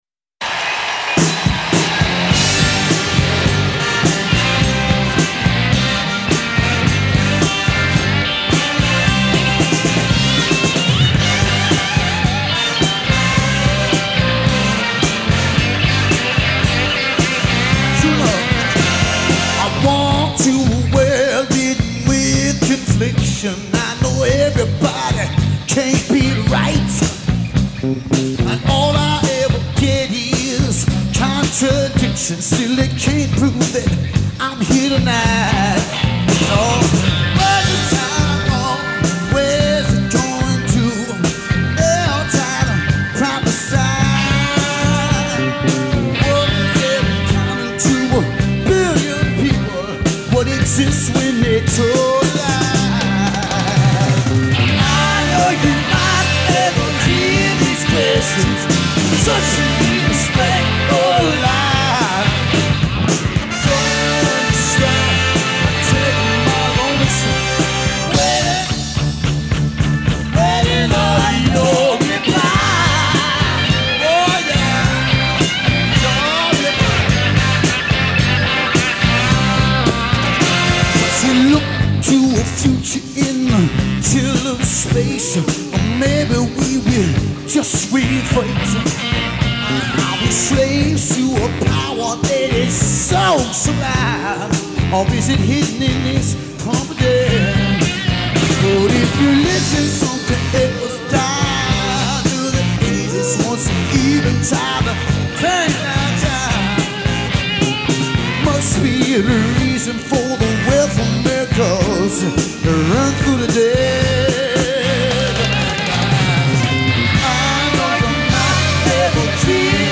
Tuned up like a Packard V-12, the sonic assault